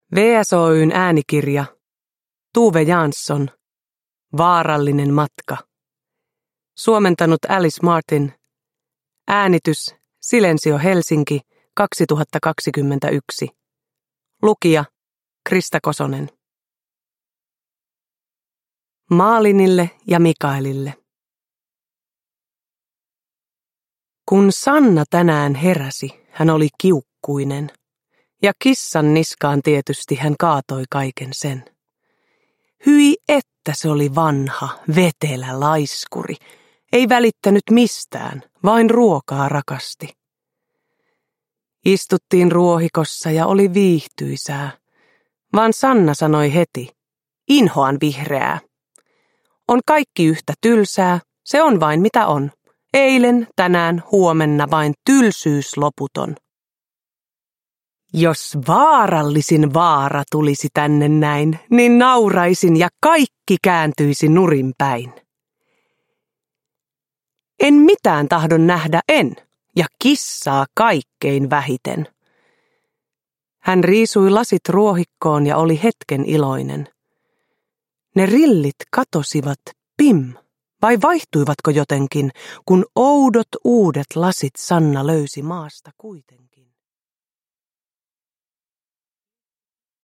Vaarallinen matka (uusi suomennos) – Ljudbok – Laddas ner
Uppläsare: Krista Kosonen